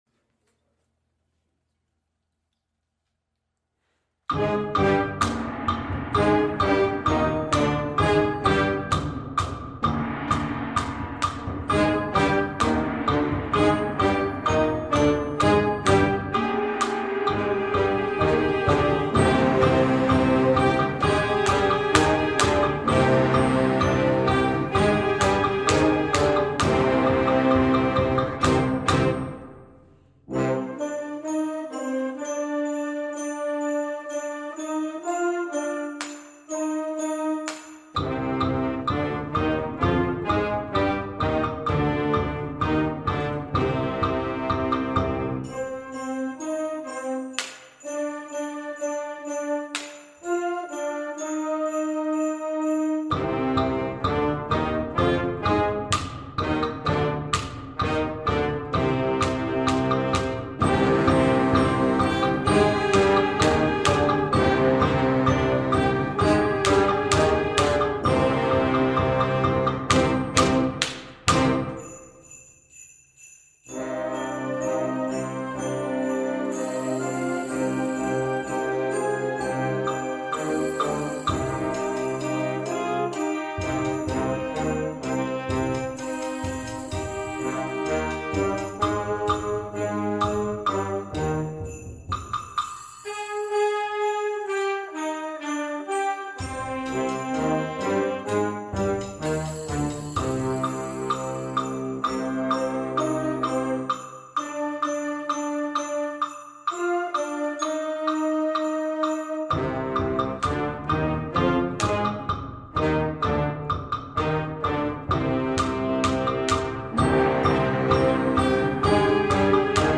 Works for Concert Band and String Orchestra
New Concert Band Music NEW!